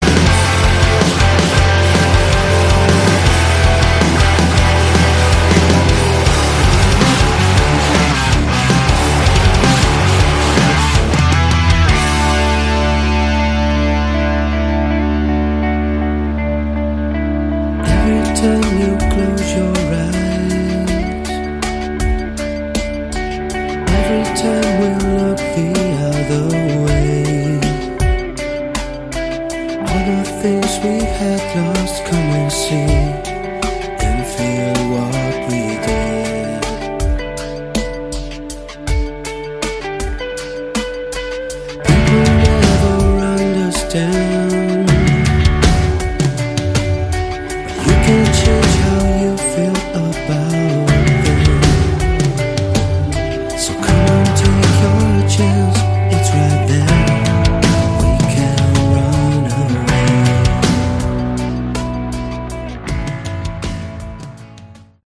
Category: Modern Hard ROck
vocals, guitars
guitars
drums
bass